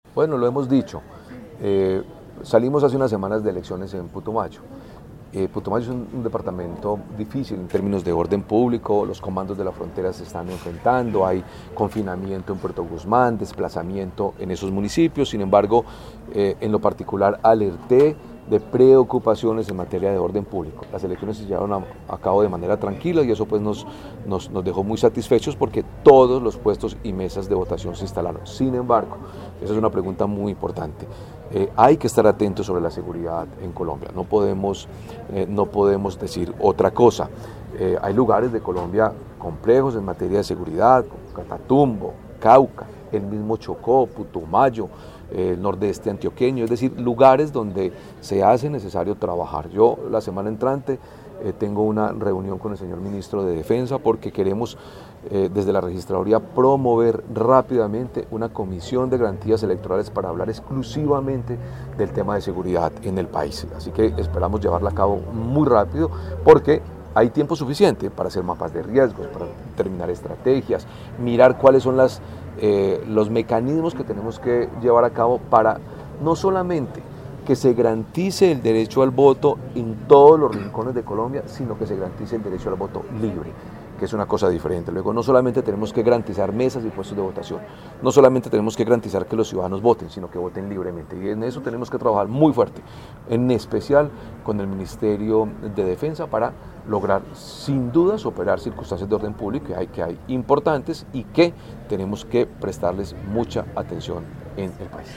Así lo confirmó desde Armenia, Hernán Penagos, Registrador Nacional del Estado Civil
Desde Armenia, el Registrador Nacional del Estado Civil, Hernán Penagos habló de la seguridad para las próximas elecciones de congreso y presidenciales en el 2026.